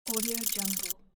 Cannon_Movement_1.mp3